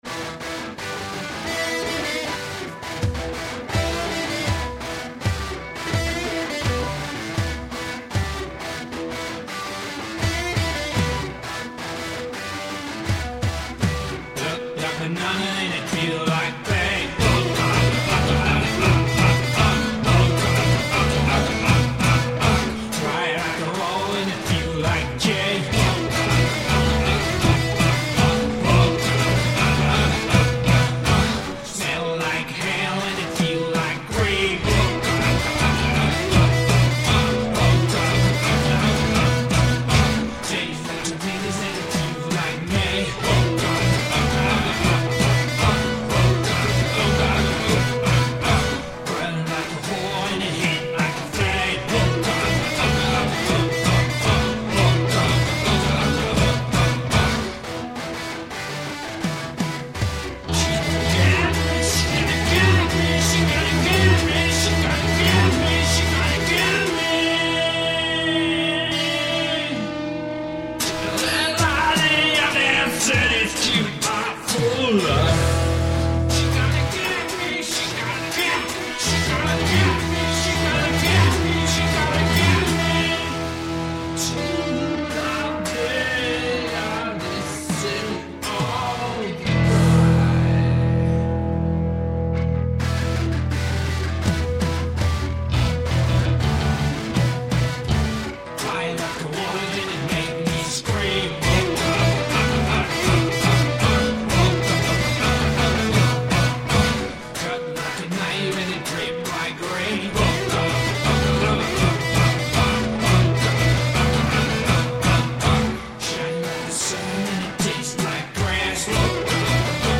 Rock/Blues MP3
Tribal rhytm.